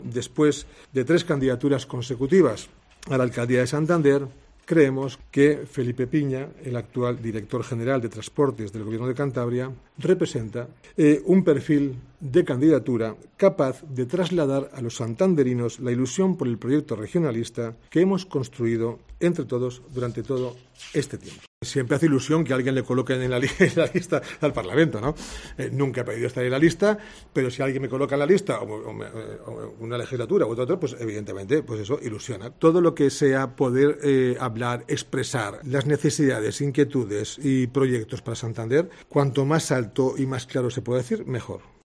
Fuentes Pila anuncia la candidatura de Piña y habla de su futuro